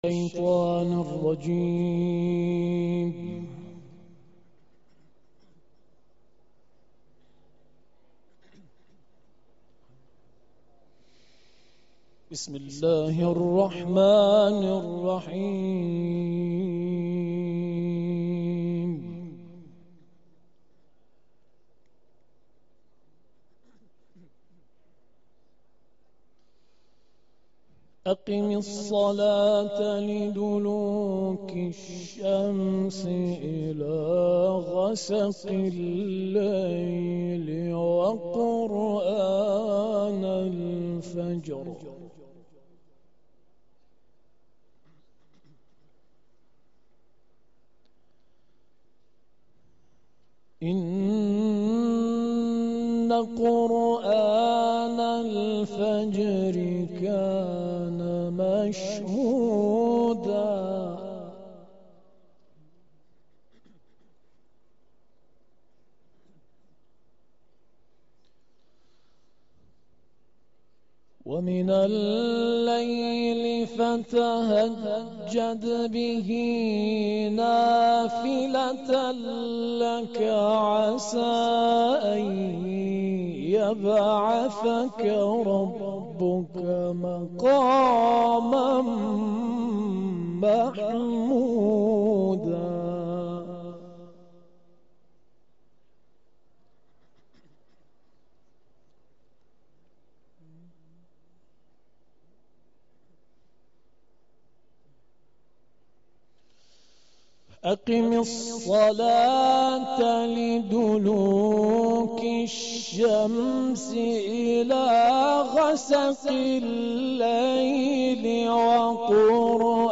دانلود قرائت سوره های اسرا و علق